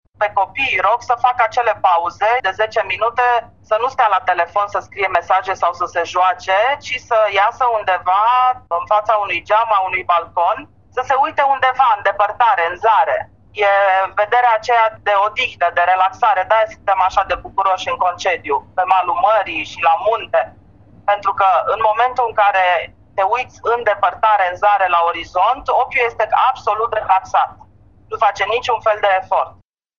Medicul braşovean